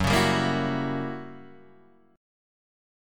F#7b9 chord